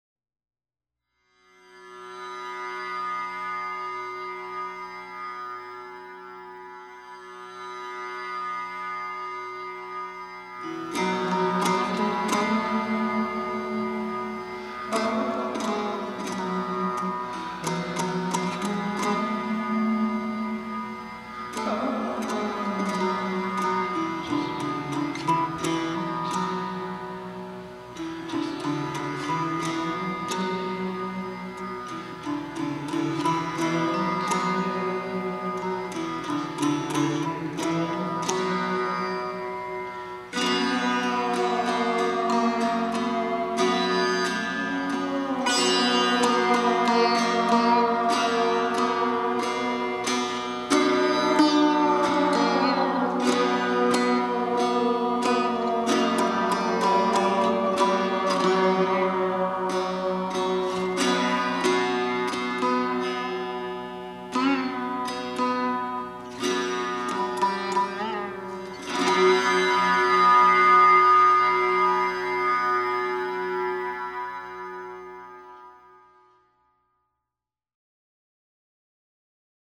ragas
pure mainlined sunshine-pop